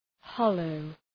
Προφορά
{‘hɒləʋ}